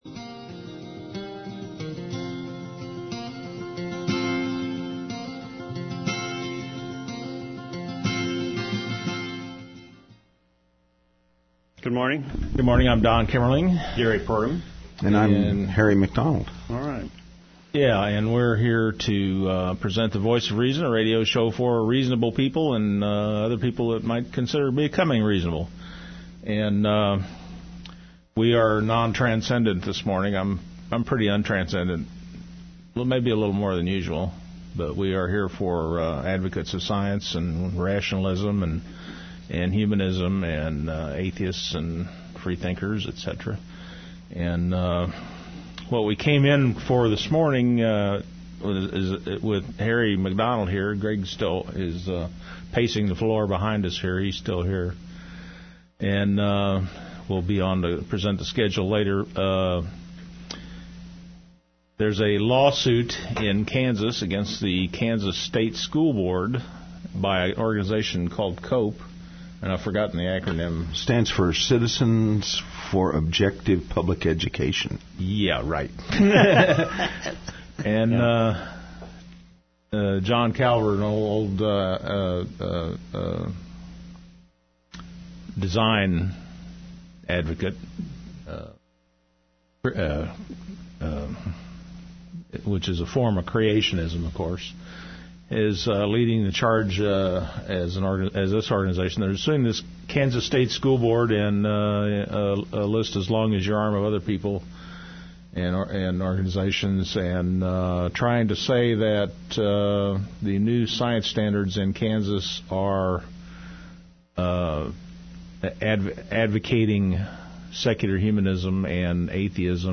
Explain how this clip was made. I appeared on the radio show, Voices of Reason, on KKFI, 90.1 FM, on Dec. 1, to discuss the lawsuit attempting to prevent the implementation of NGSS. In a twist of history, I find myself defending the actions of the state board on evolution instead of protesting.